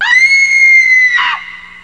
Scary Screams
SCREAM.WAV